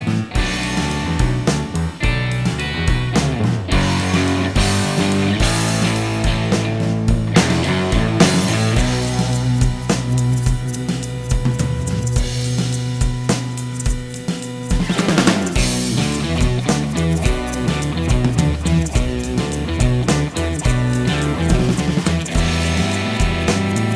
Minus Final Guitar Solo Only Indie